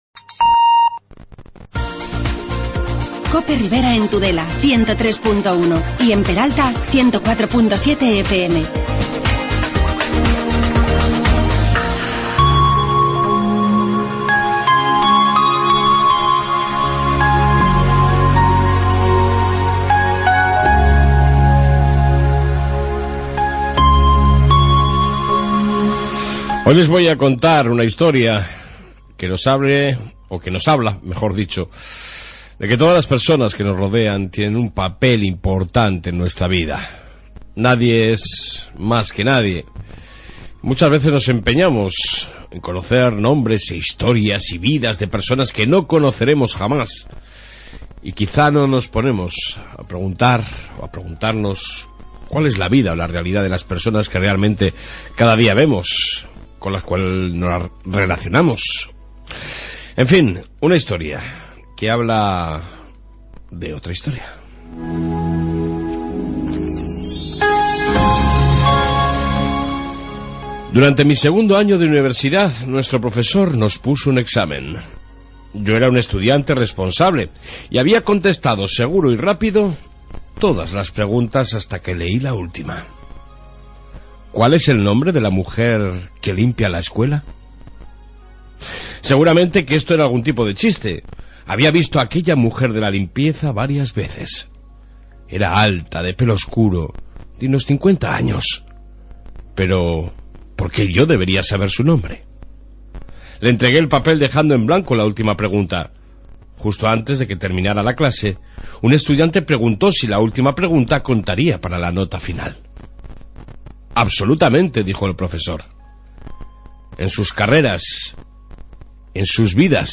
AUDIO: Hoy 27 de mayo, reflexión diaria, policia Municipal, noticias riberas y entrevista con ademna en el día mundial de la esclerosis...